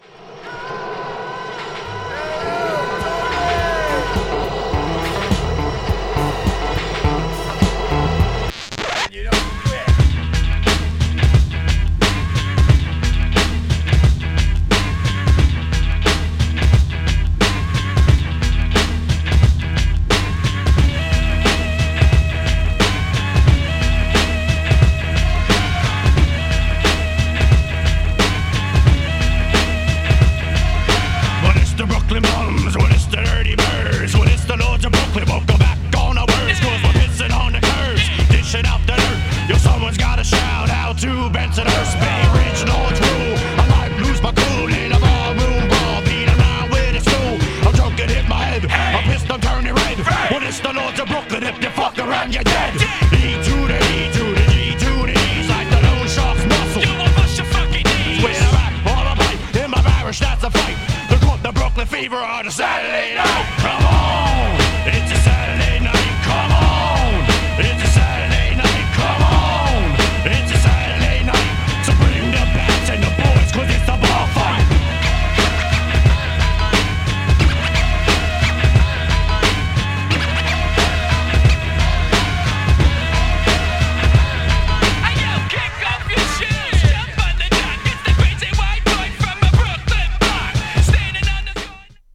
NYC発の白人ミクスチャーバンドによるハードコアなHIP HOP!!
GENRE Hip Hop
BPM 91〜95BPM